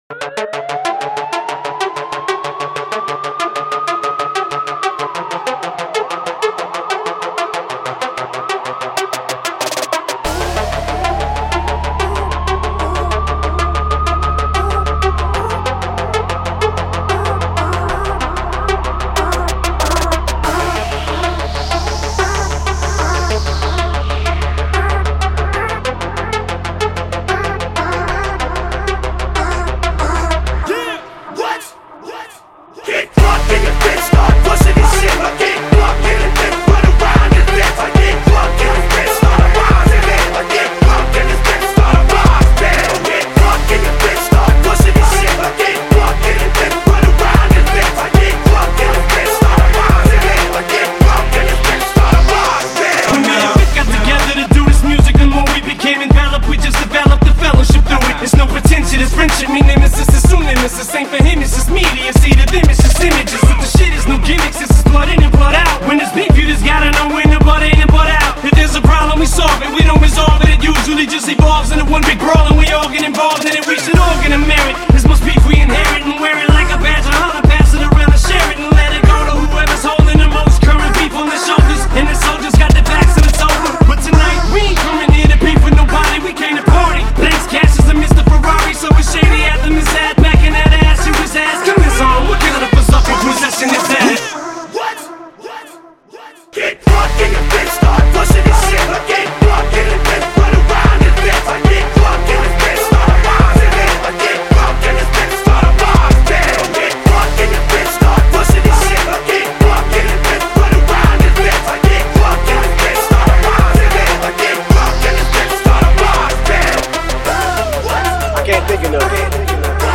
Клёвый рэп